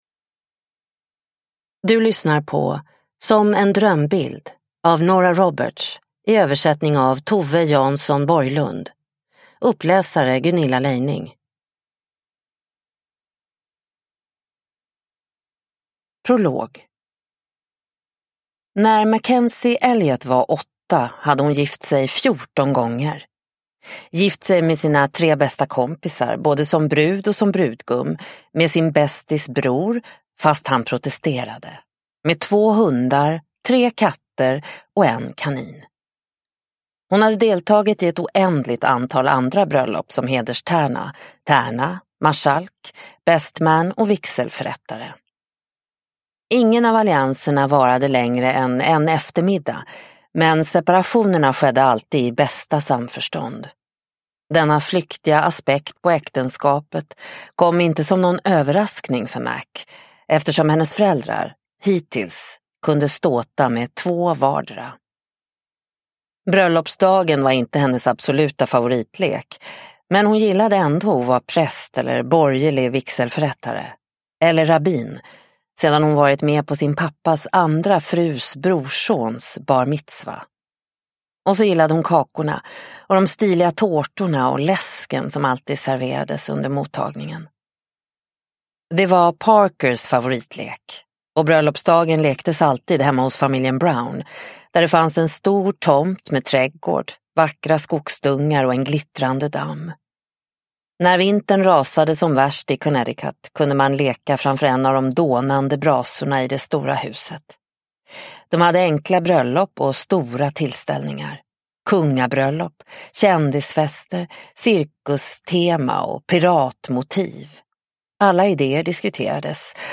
Som en drömbild – Ljudbok – Laddas ner